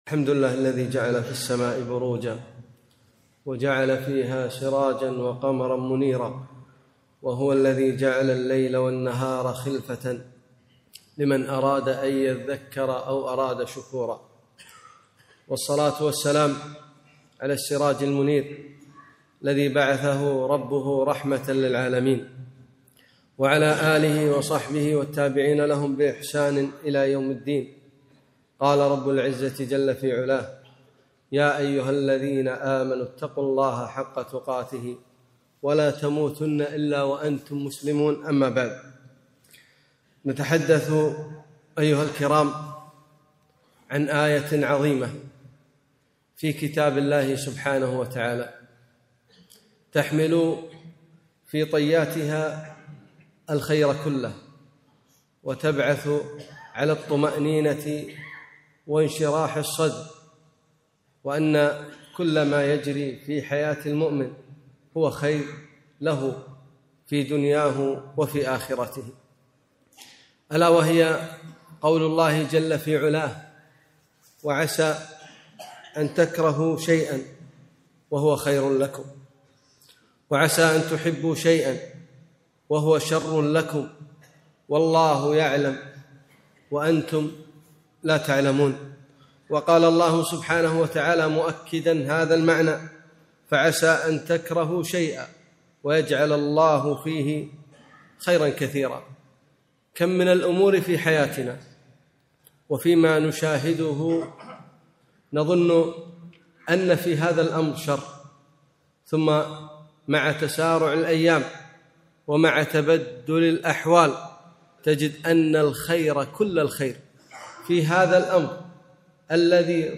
خطبة - خيرة الله تبارك وتعالى - دروس الكويت